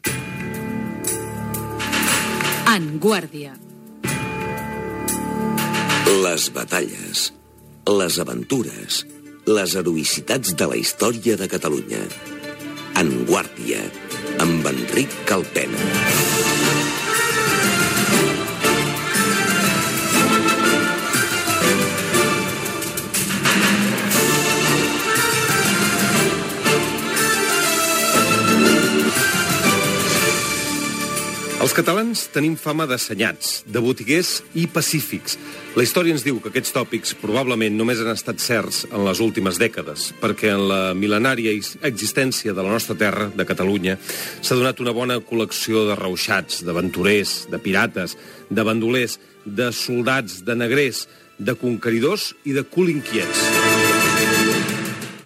Careta del programa i comentari sobre els catalans
Divulgació